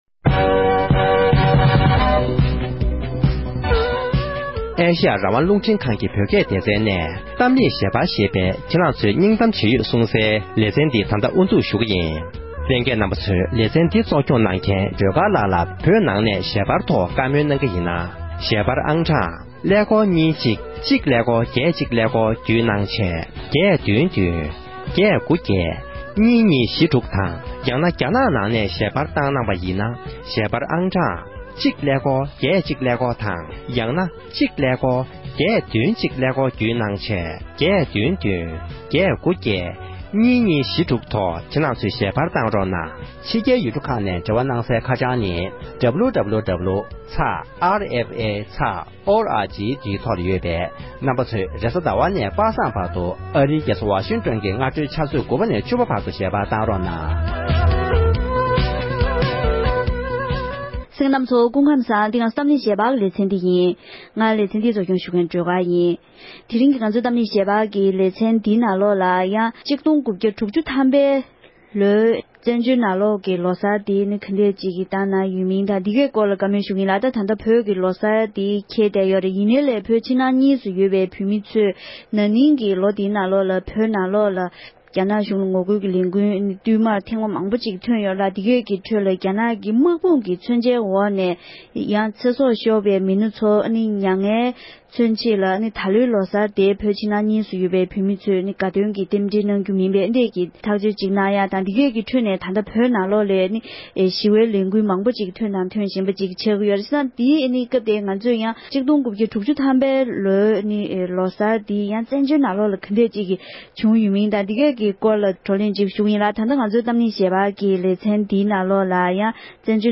ལྷ་ས་ནས་ཞལ་པར་གནང་མཁན་བོད་མི་ཞིག